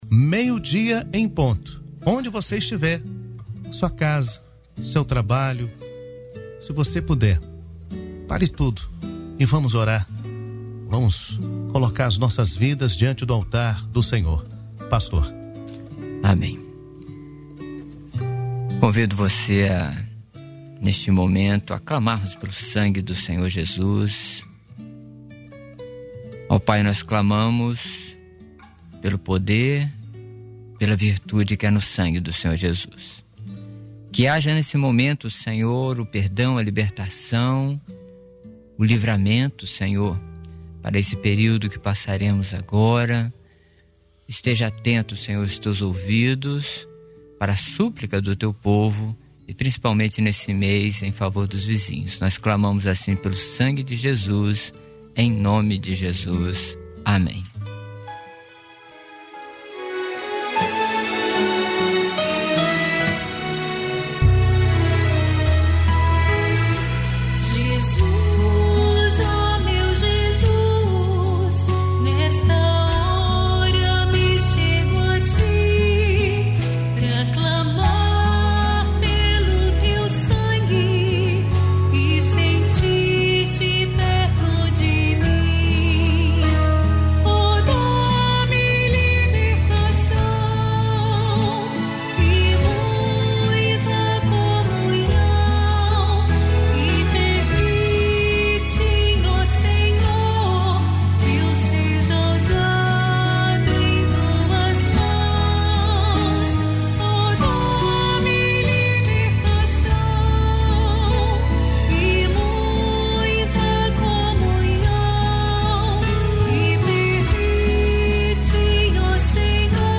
Culto de oração da Igreja Cristã Maranata.